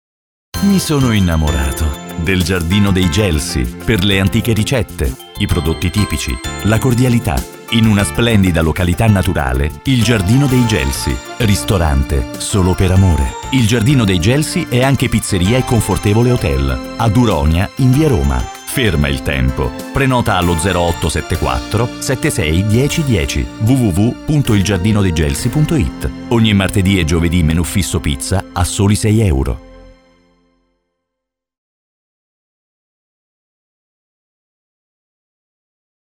Scarica lo spot radiofonico!!! il giardino dei gelsi.mp3